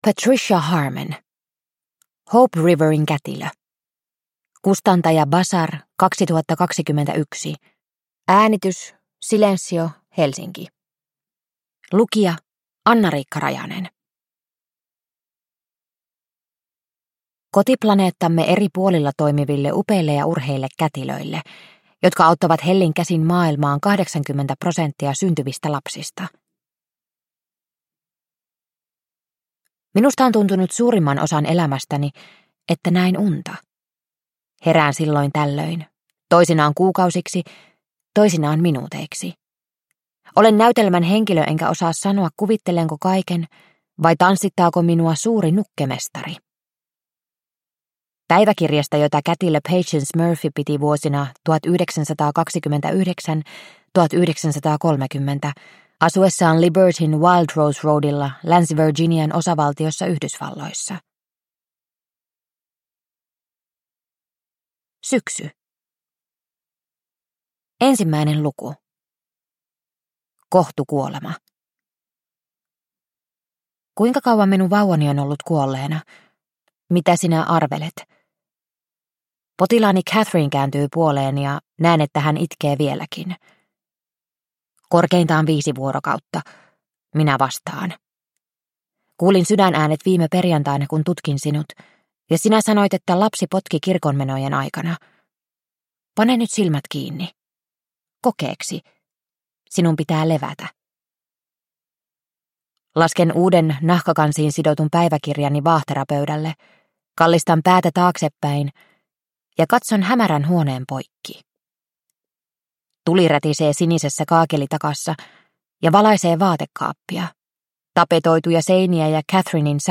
Hope Riverin kätilö – Ljudbok – Laddas ner